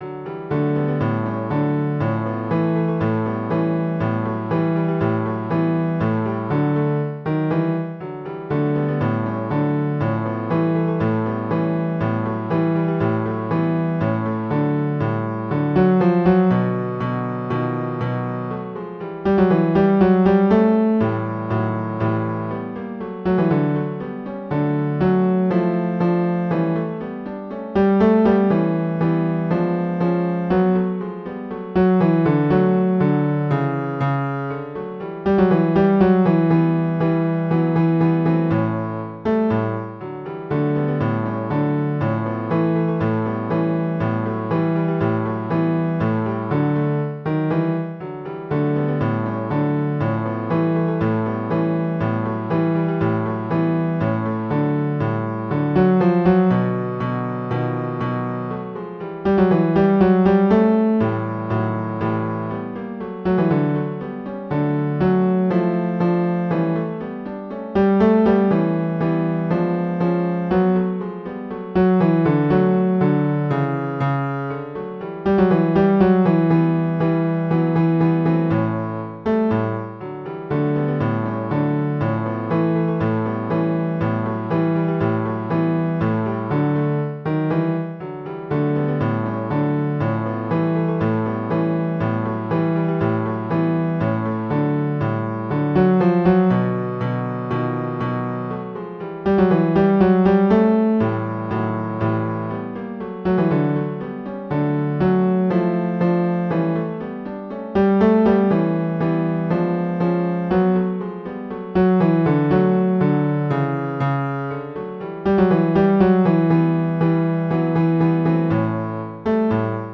Les voix individuelles sont mises en avant-plan sonore avec en arrière-fond les trois autres voix.
Tutti Ténor 1 Ténor 2 Baryton Basse